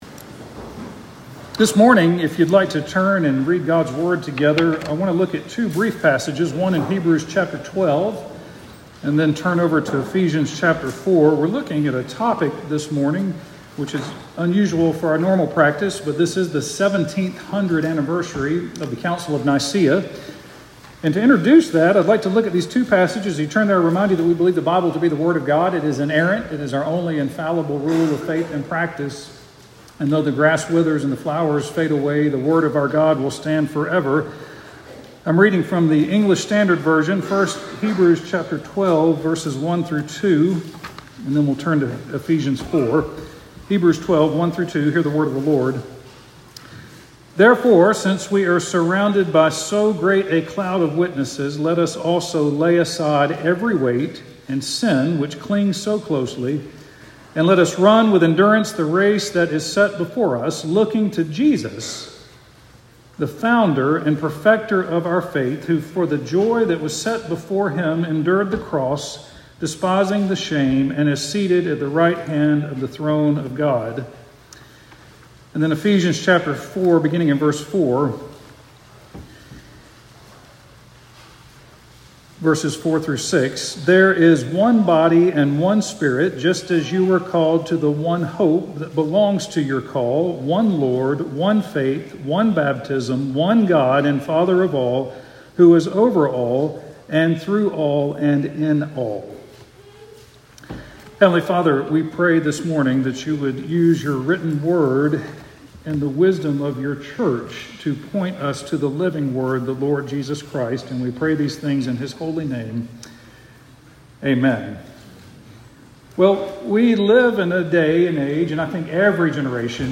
We Believe: Celebrating the Council of Nicaea Sermon